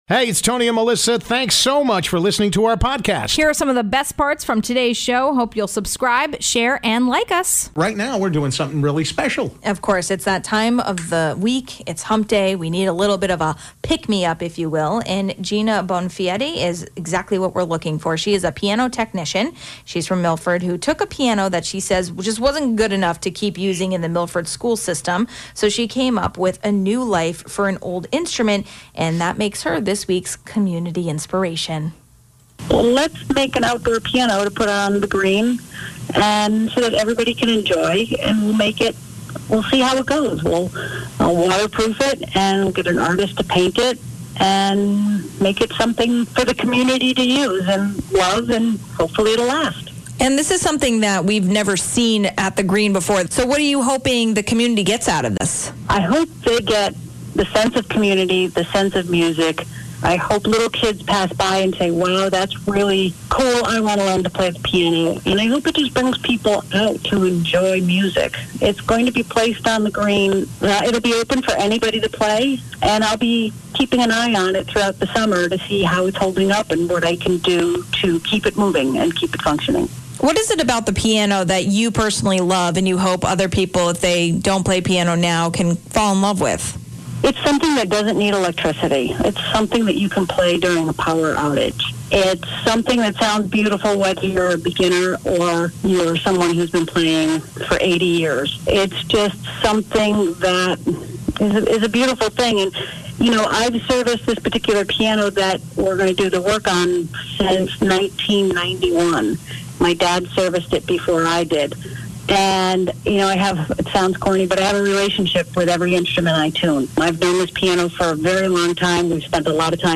Mayor Joe Ganim talks casino hopes